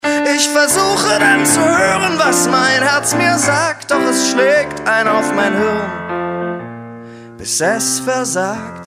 So klingt ein Song Slam in Dresden.
Du hörst: Ob du jetzt rappst oder ein zartes Liebeslied mit der Gitarre singst, spielt bei einem Song Slam keine so große Rolle.
Sonslam-Dresden.mp3